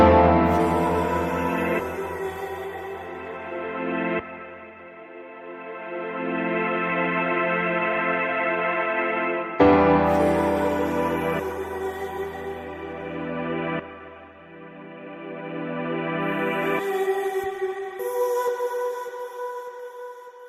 LOOP - FLOOR IT.wav